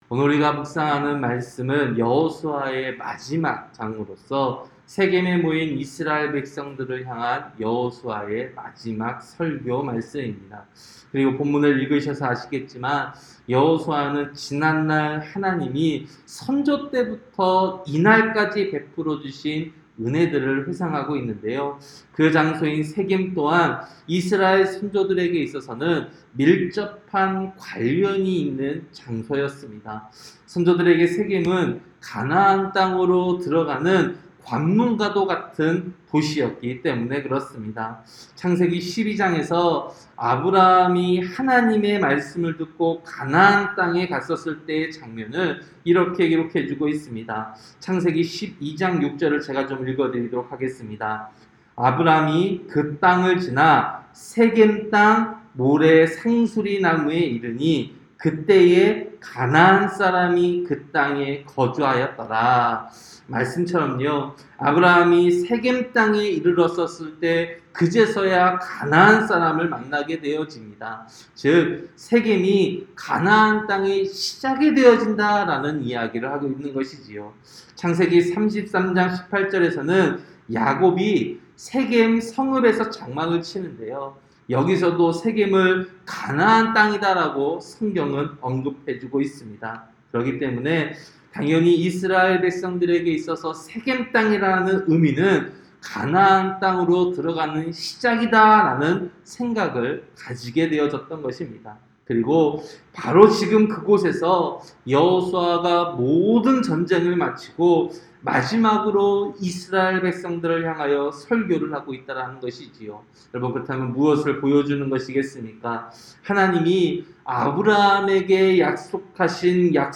새벽설교-여호수아 24장